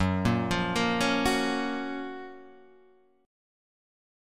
F# Major 7th